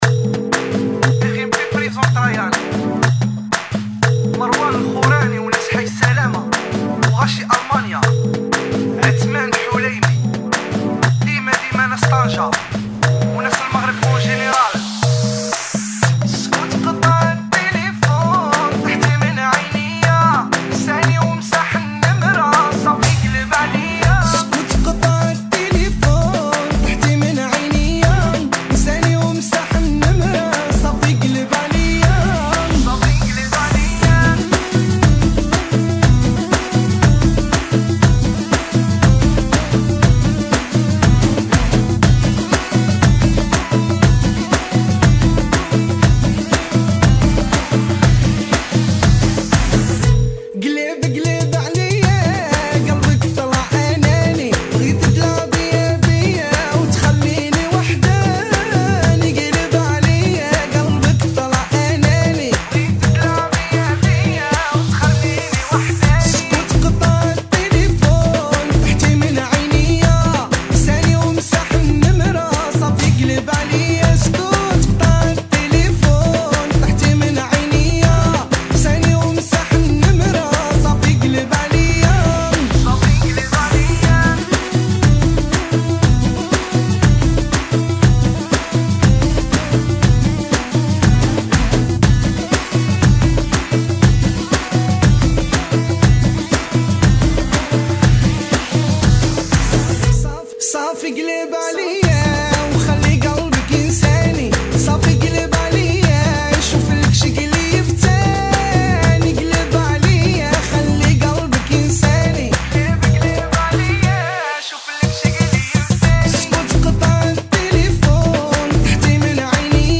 [ 120 Bpm ]